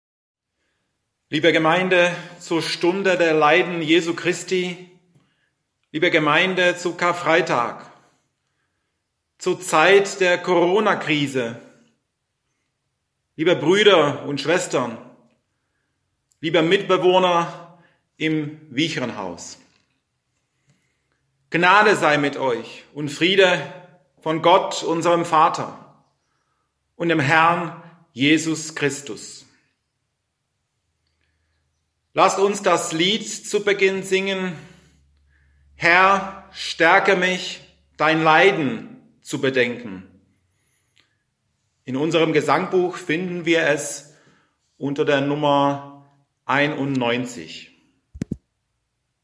Audio-Gottesdienst aus der Kirche Mittelherwigsdorf
an der Orgel
Vorspiel
Gruß